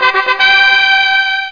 fanfa_fg.mp3